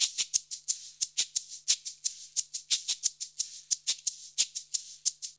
Index of /90_sSampleCDs/USB Soundscan vol.56 - Modern Percussion Loops [AKAI] 1CD/Partition A/04-FREEST089